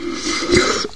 barnacle_slurp1.ogg